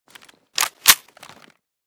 pp2000_unjam.ogg.bak